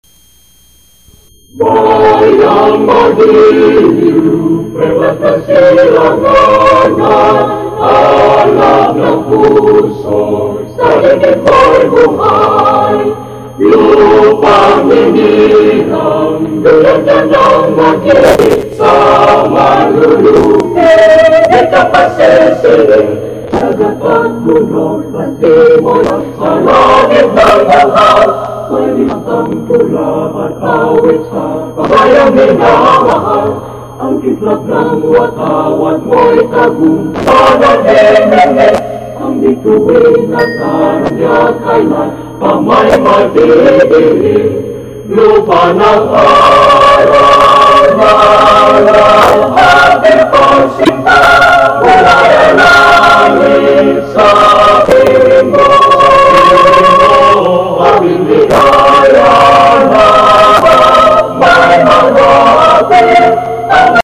Repertoir - AUP Indonesian Chorale
Note: Sound quality may differ from original recording.